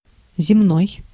Ääntäminen
Synonyymit ordinary everyday vulgar boring tedious routine banal jejune earthly workaday commonplace profane worldly terrestrial unremarkable nondescript Ääntäminen US Tuntematon aksentti: IPA : /ˌmən.ˈdeɪn/